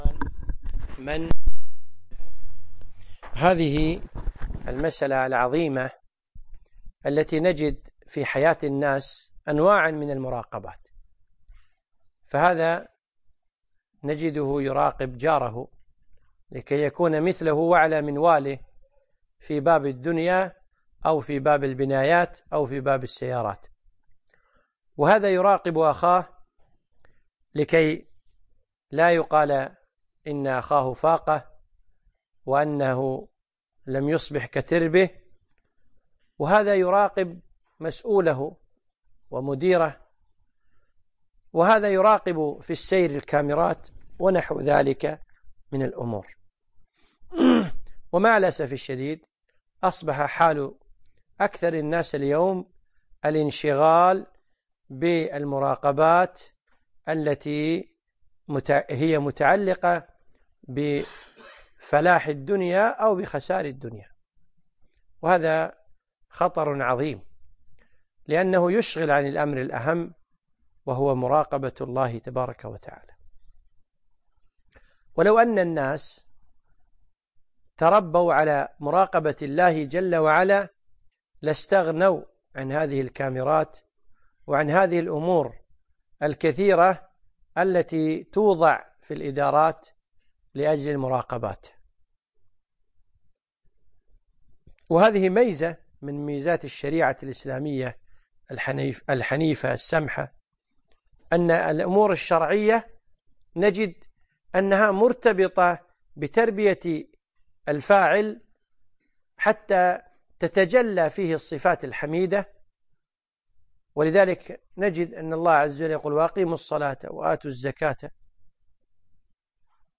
يوم الأثنين 7 جمادى الأخر 1438 الموافق 6 3 2017 في مسجد الهاجري الجابرية